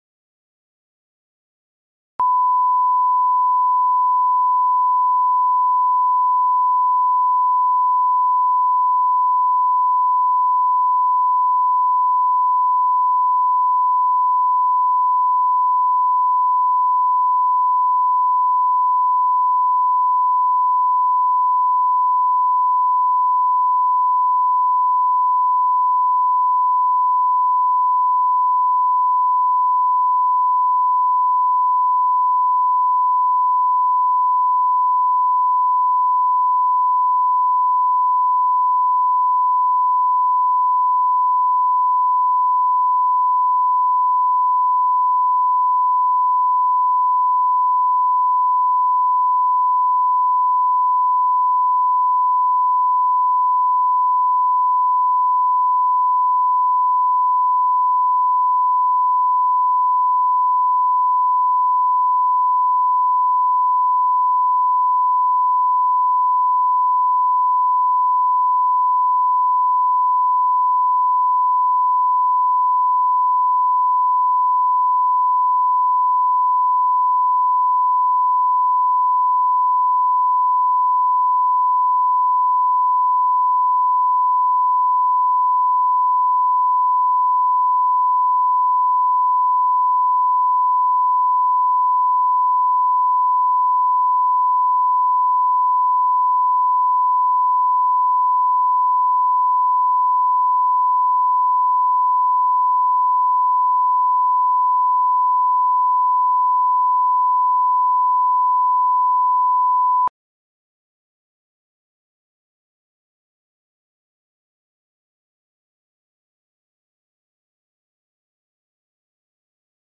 Aудиокнига Одиночный танец Автор Александр Левин.